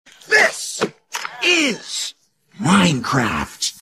This is Minecraft sound effects